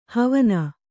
♪ havana